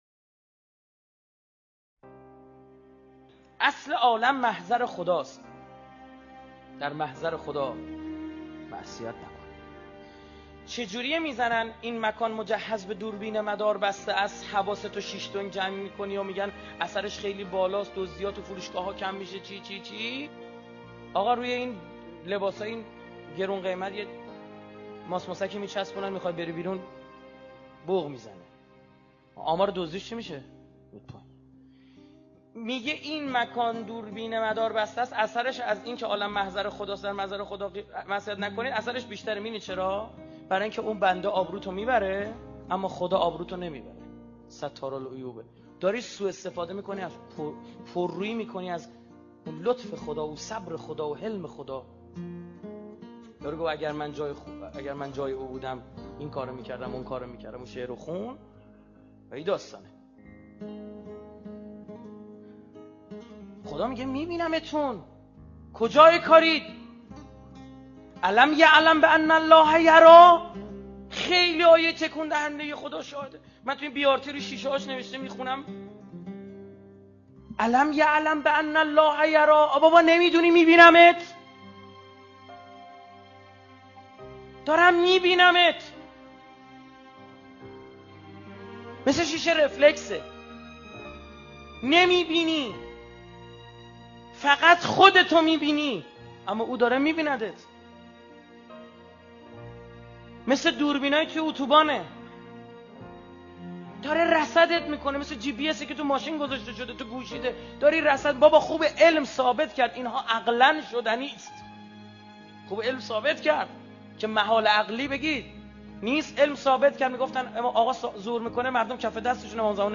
سخنرانی استاد رائفی پور/ عالم محضر خداست در محضر خدا معصیت نکنیم